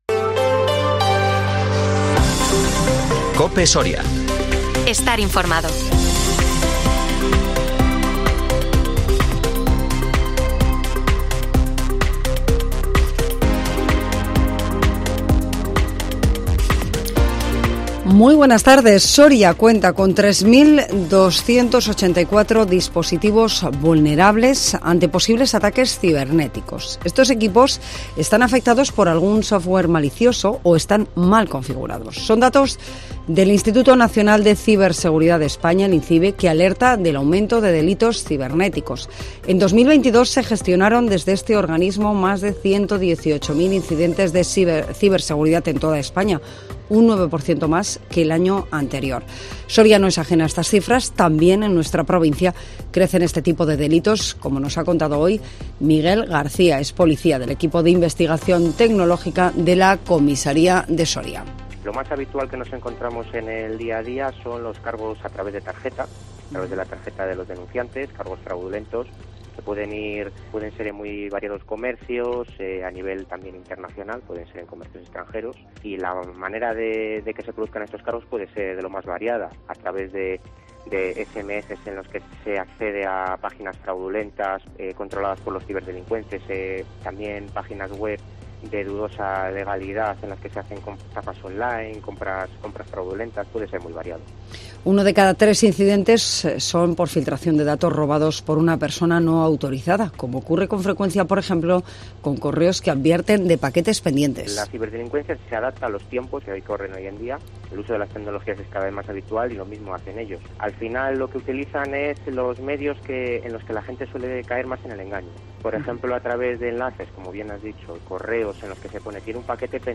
AUDIO: Informativo del 25 de octubre en COPE Soria